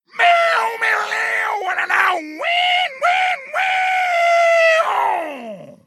mouth-guitar_01
Category: Games   Right: Personal